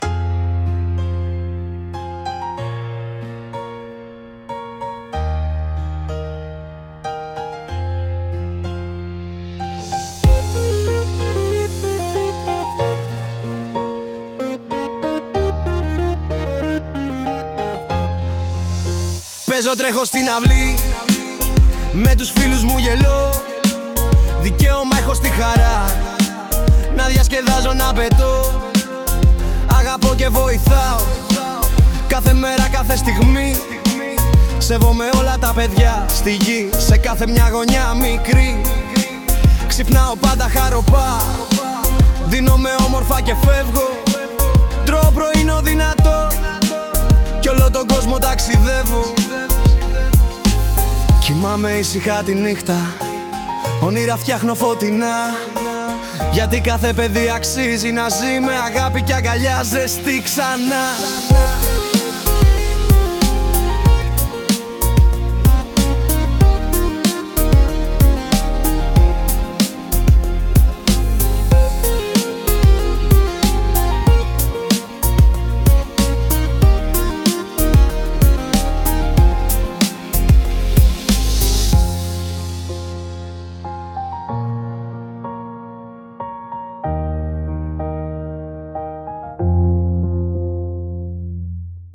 Τραγούδια που δημιουργήσαμε με τη βοήθεια της Τεχνητής Νοημοσύνης (ChatGpt, Suno)